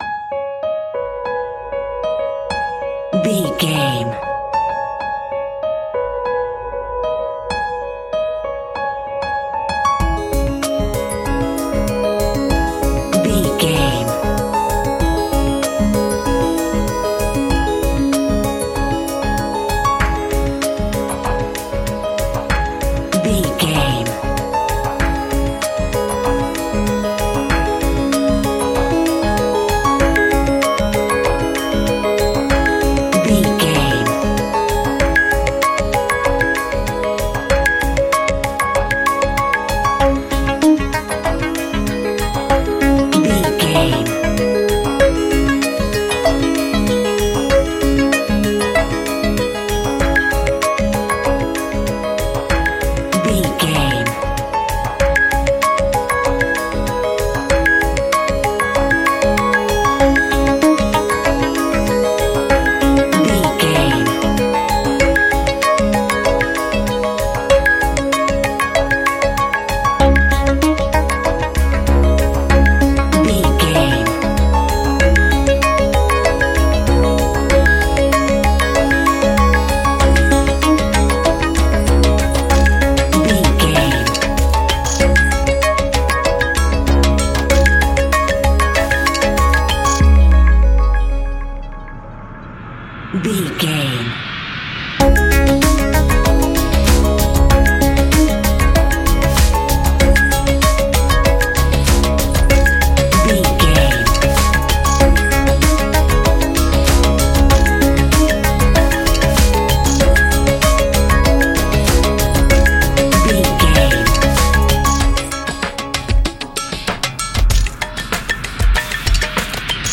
Ionian/Major
C♯
electronic
techno
trance
synths
synthwave
instrumentals